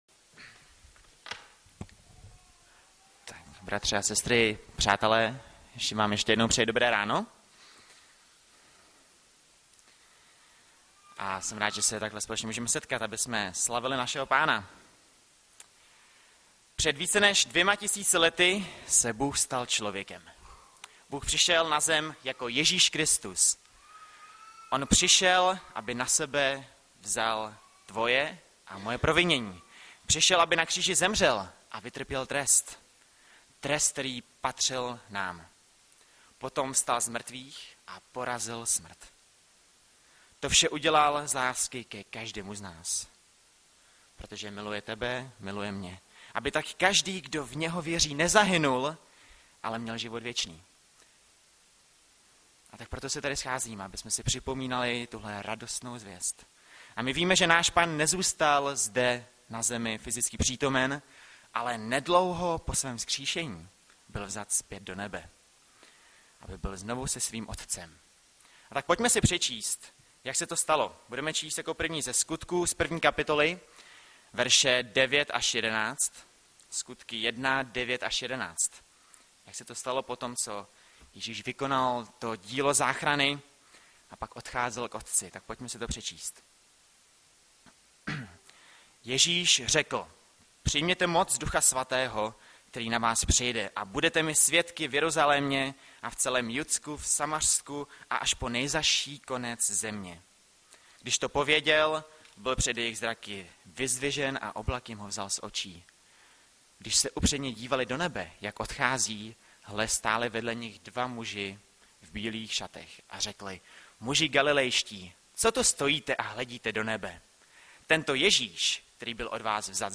Hlavní nabídka Kázání Chvály Kalendář Knihovna Kontakt Pro přihlášené O nás Partneři Zpravodaj Přihlásit se Zavřít Jméno Heslo Pamatuj si mě  01.01.2017 - TĚŠÍŠ SE NA PŘÍCHOD SVÉHO PÁNA?